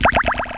snd_131_DRIPITY.WAV.wav